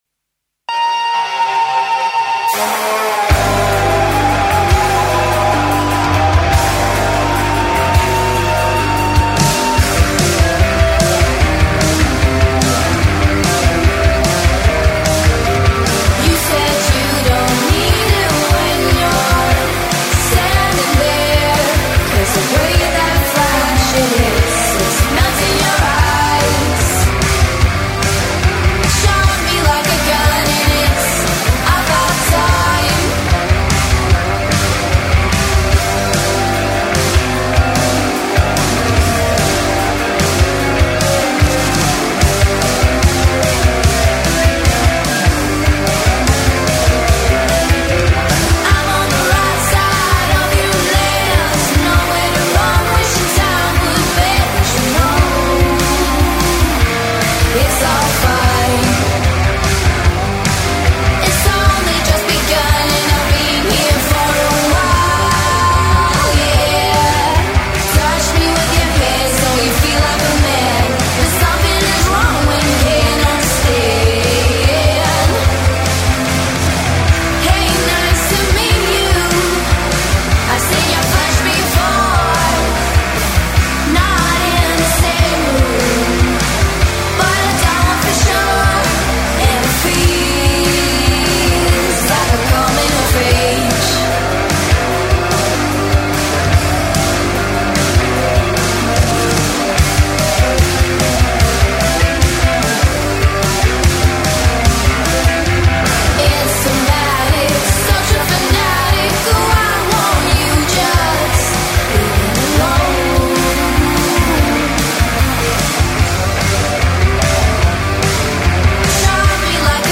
surf-rock stylings
guitar, vocals